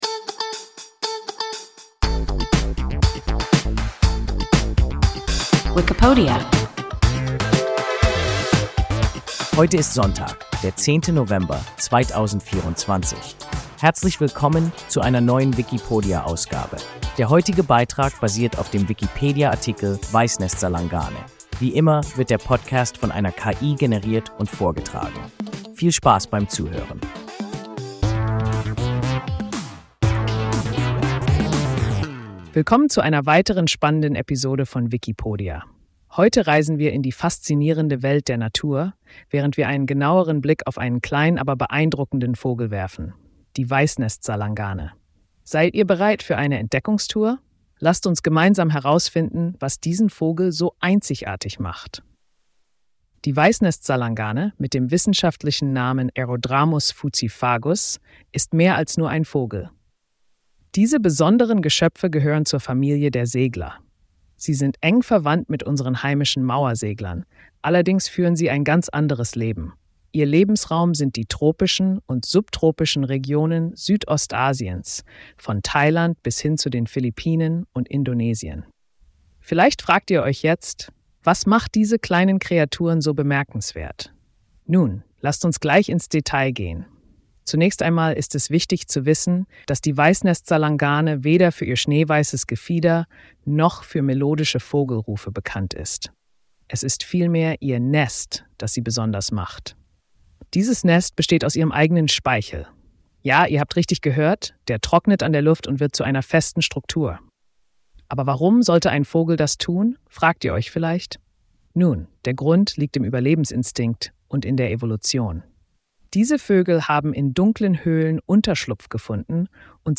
Weißnestsalangane – WIKIPODIA – ein KI Podcast